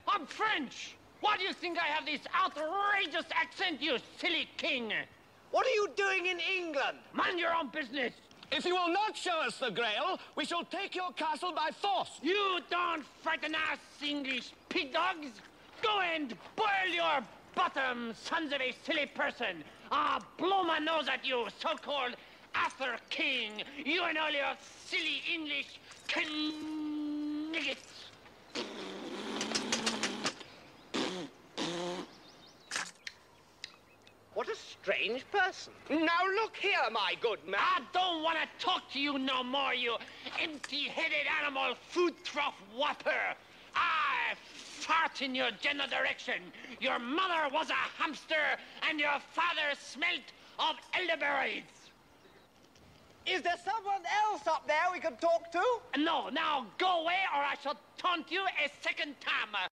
Random Monty Python audios in the middle of Epcot, on the bridge between UK and France pavilions.